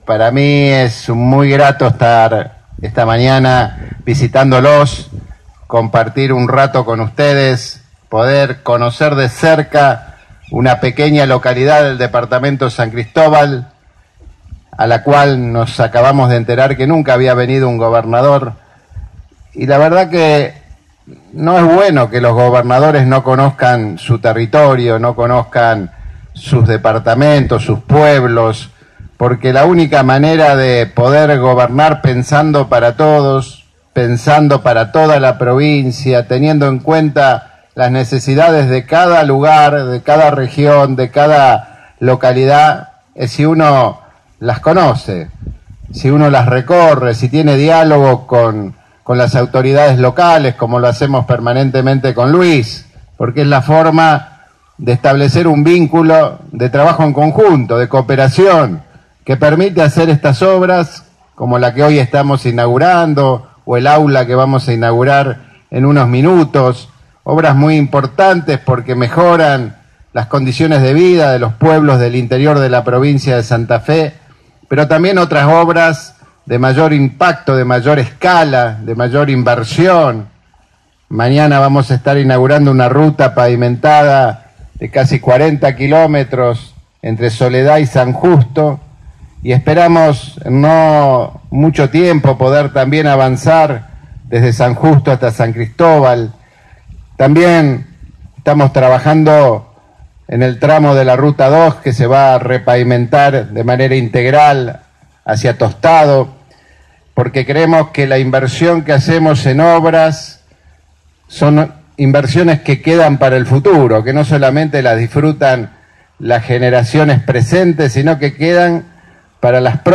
Inauguración del natatorio comunal en La Cabral.
Miguel Lifschitz en La Cabral